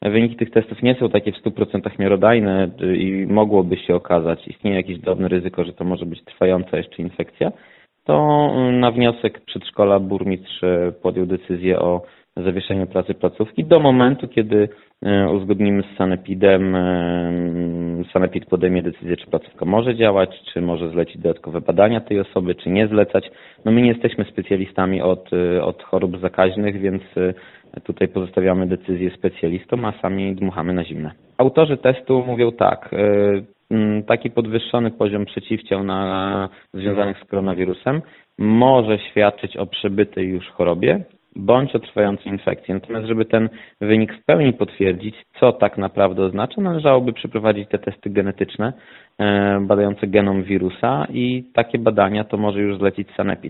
Jak tłumaczy Radiu 5 Filip Chodkiewicz, zastępca burmistrza Augustowa, pozytywny wynik nie oznacza jeszcze, że kobieta jest chora.